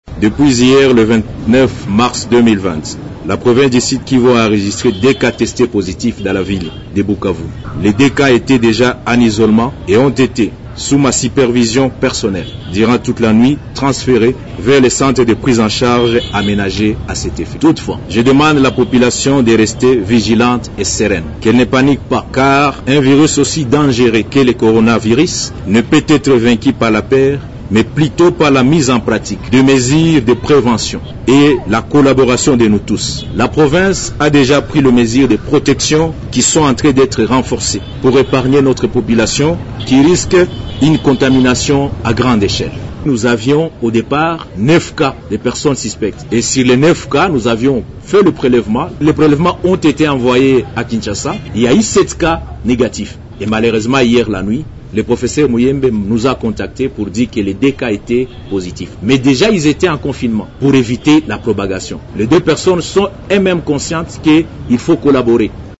Dans sa communication ce matin à la presse, le gouverneur Theo Kasi Nguabidje rassure la population que les deux malades ont vite été transférés au centre de traitement aménagé pour la circonstance et que leur état de santé est stable.